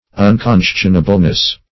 ([u^]n*k[o^]n"sh[u^]n*[.a]*b'l), a.